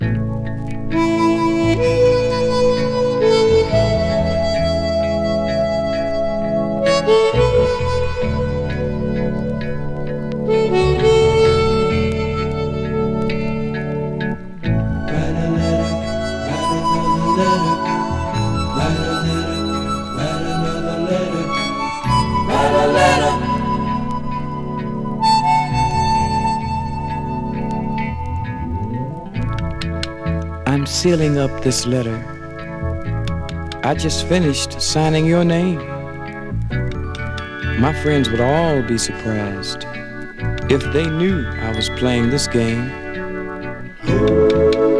Soul　USA　12inchレコード　33rpm　Stereo